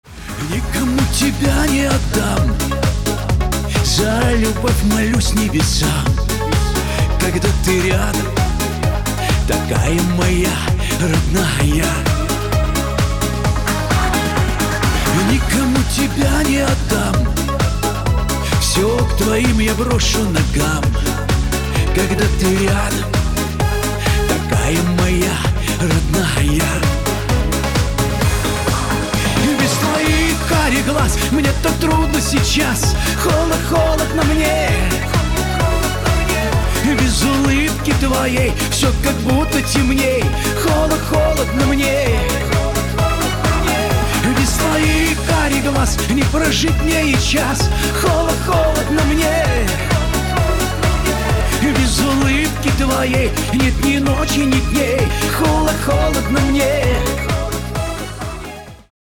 • Качество: 320, Stereo
мужской вокал
русский шансон